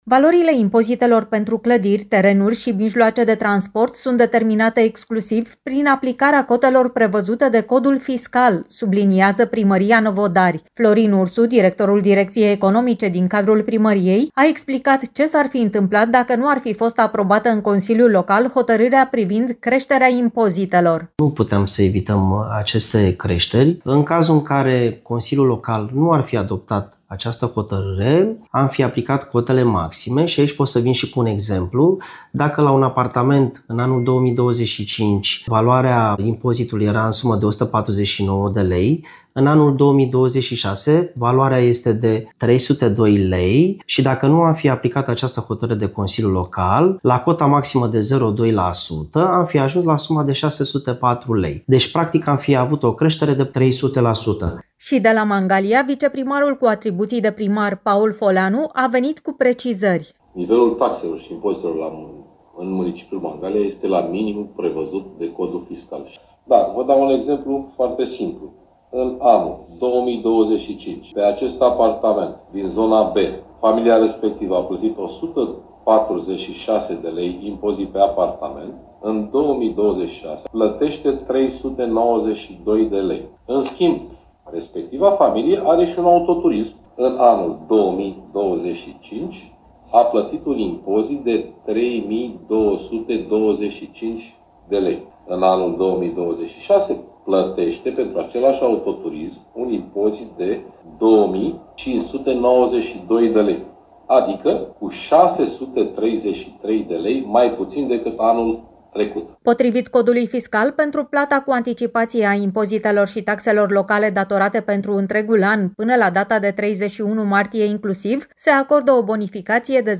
Explicații vin și de la Primăria Mangalia, unde viceprimarul cu atribuții de primar Paul Foleanu subliniază că nivelul taxelor și impozitelor este la valoarea minimă prevăzută de Codul Fiscal.